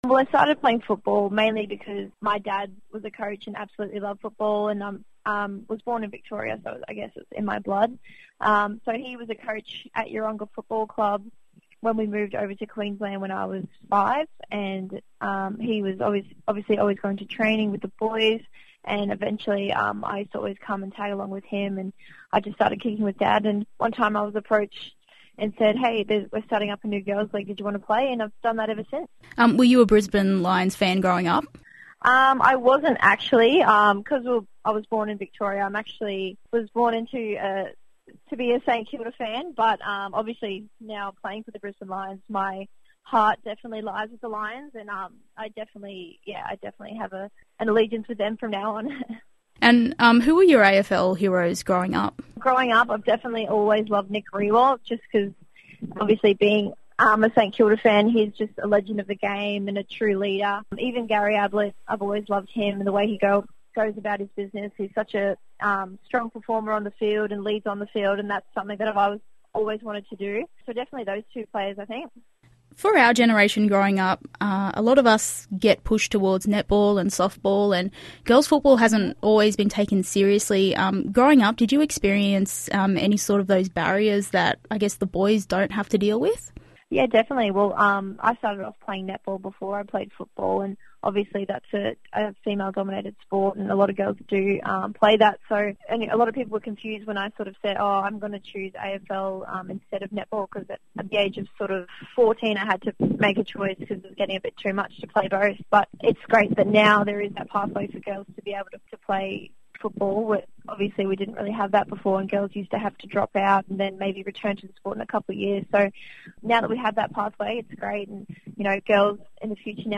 Speaking on ABC Radio, Emily Bates, the vice-captain of the newly created Brisbane Lions women's side, says after years of hard work, her love of the game has finally paid off.